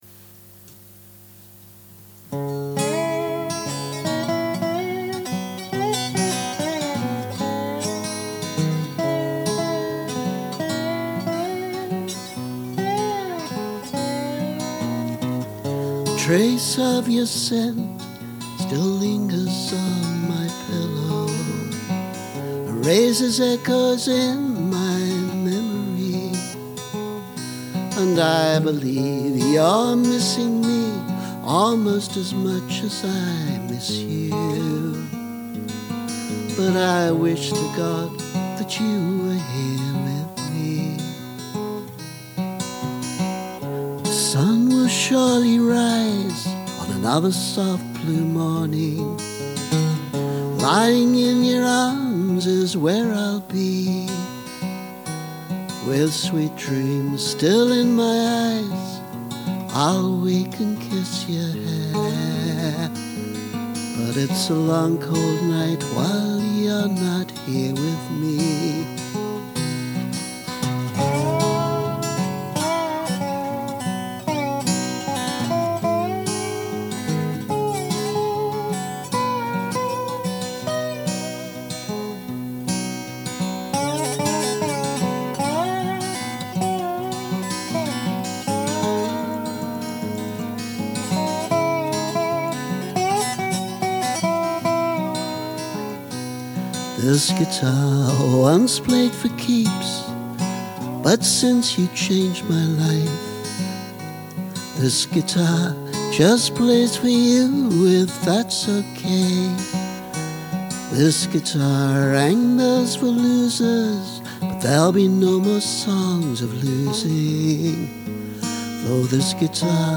However, this is rather more country than country blues.
Old-ish country-ish version: